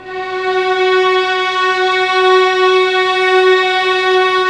Index of /90_sSampleCDs/Roland - String Master Series/STR_Vlns 6 p-mf/STR_Vls6 mf slo